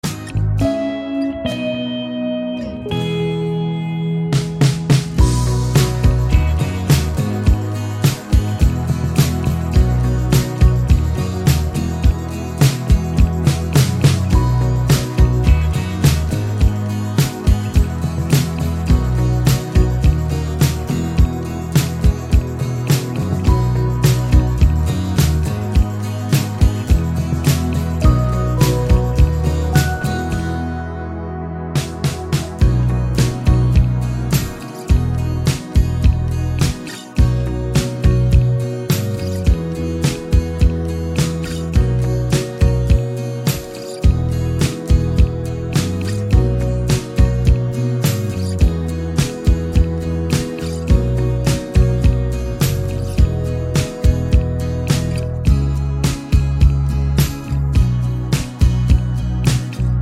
no Backing Vocals Country (Female) 3:58 Buy £1.50